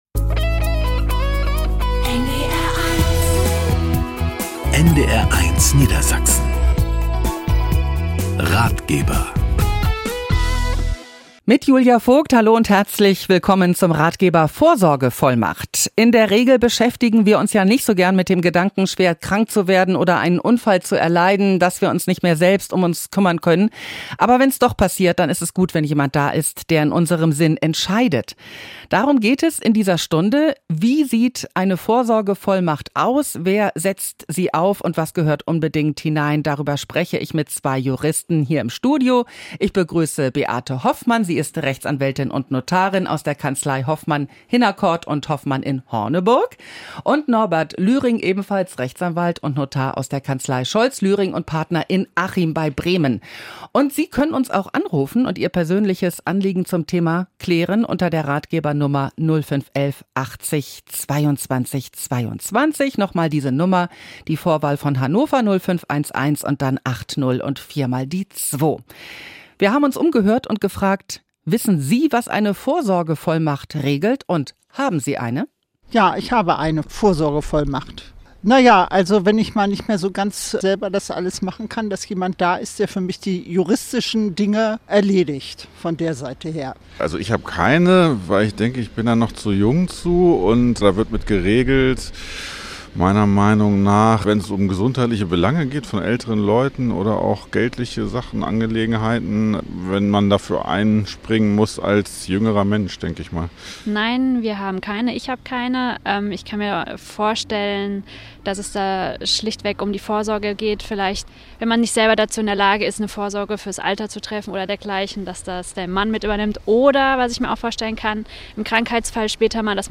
NDR-Ratgeber_GenVV-1.mp3